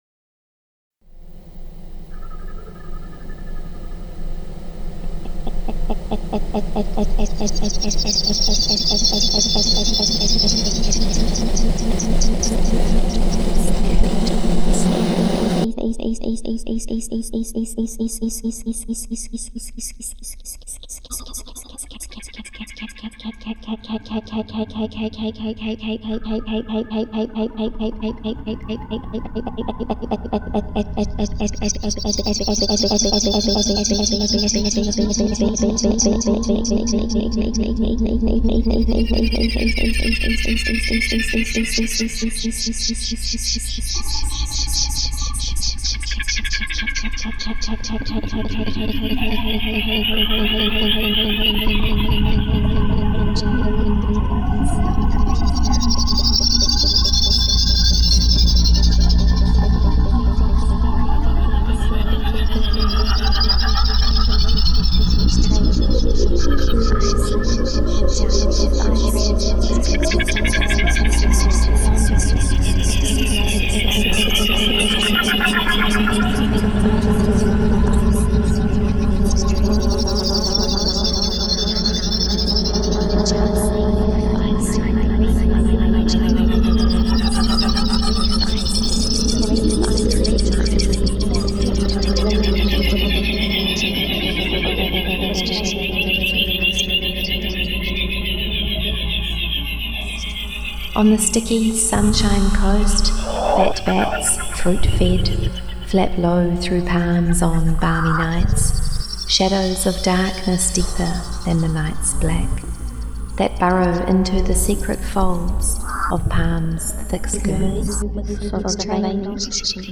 Poem